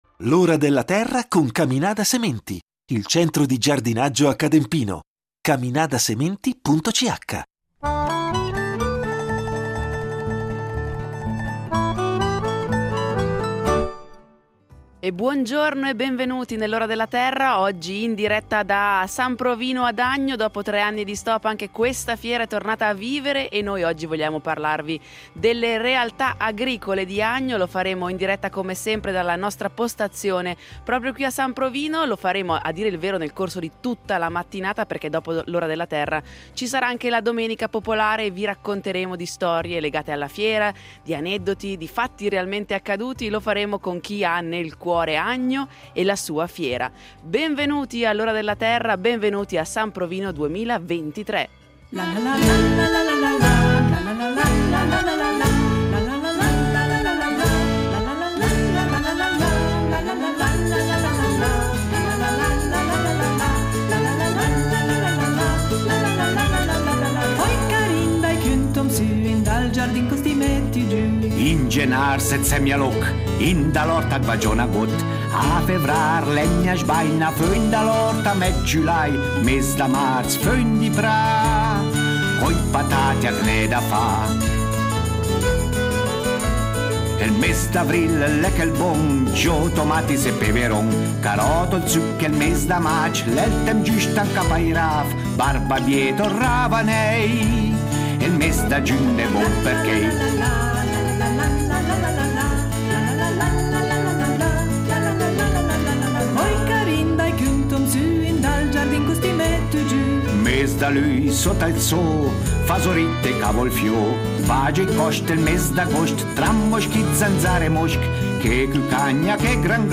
Che cosa è rimasto di agricolo nel comune di Agno? Lo scopriremo nella puntata de L’Ora della Terra in diretta dalla Fiera di San Provino.